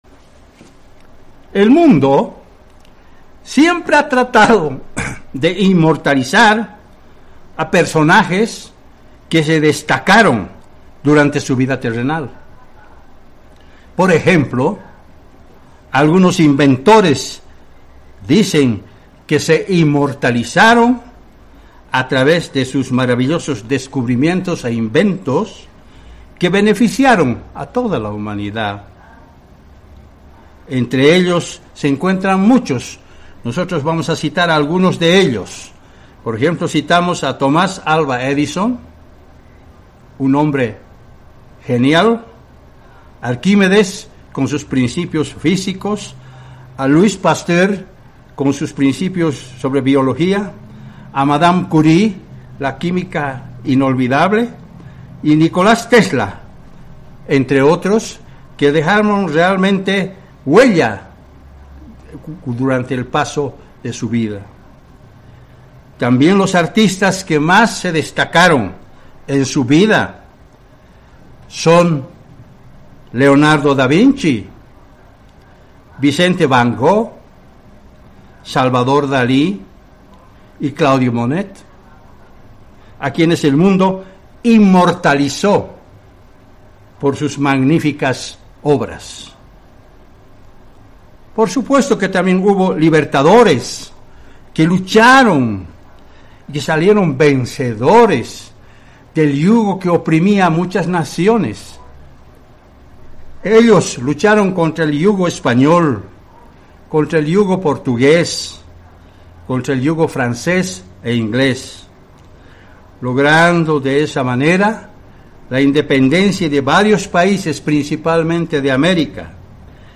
Given in La Paz